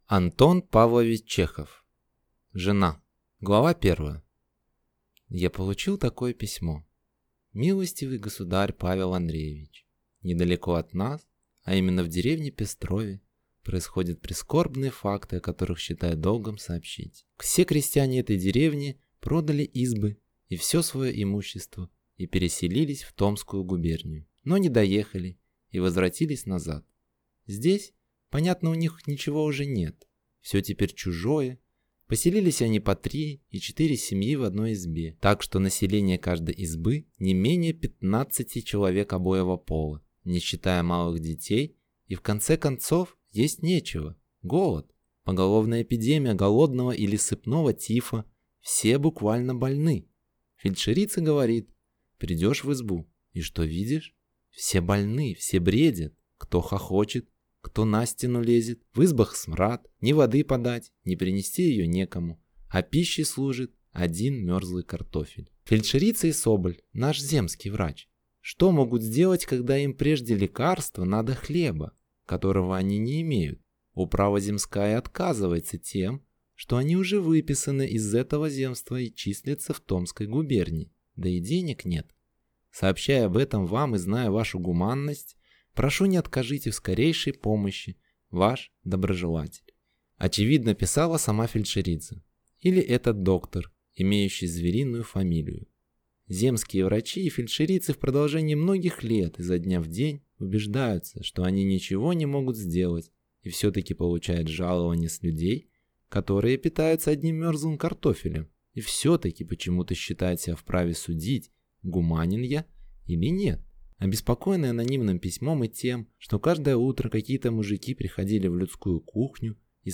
Аудиокнига Жена | Библиотека аудиокниг